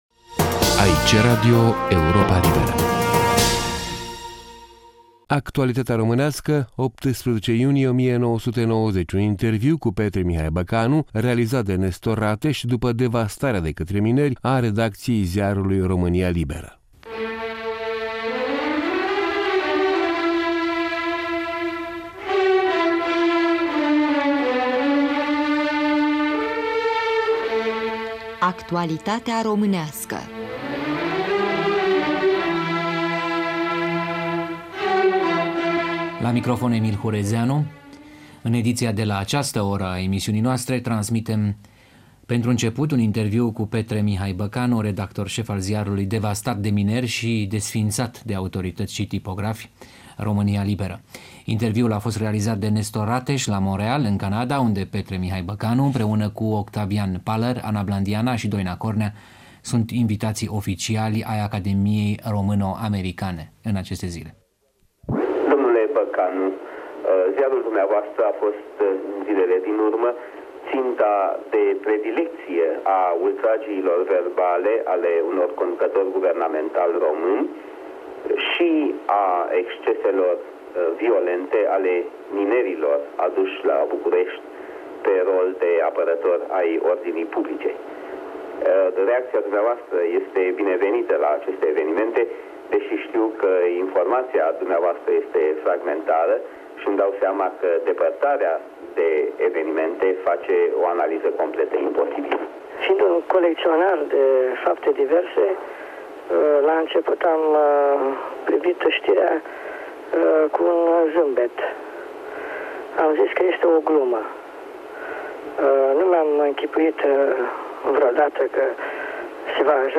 Un interviu cu Mihai Petre Băcanu, reacții în presa internațională la mineriada din iunie 1990,